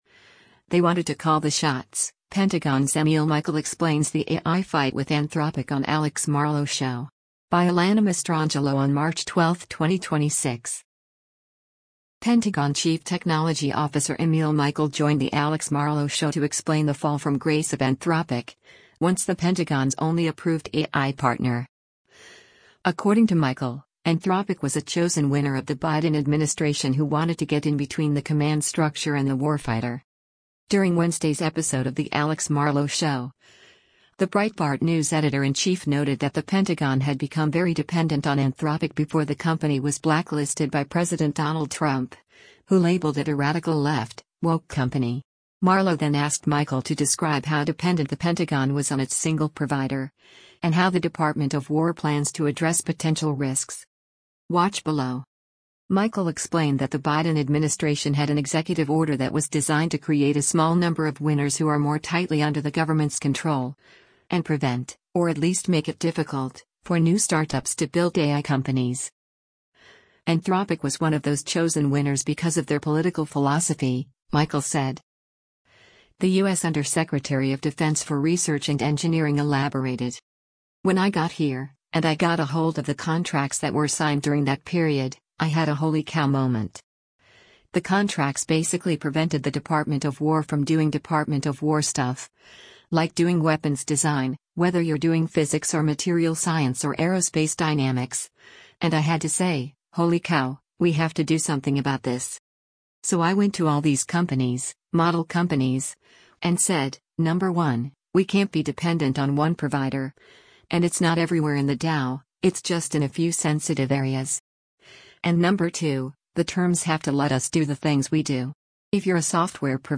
Pentagon Chief Technology Officer Emil Michael joined The Alex Marlow Show to explain the fall from grace of Anthropic, once the Pentagon’s only approved AI partner.
Marlow then asked Michael to describe how dependent the Pentagon was on its single provider, and how the Department of War plans to address potential risks.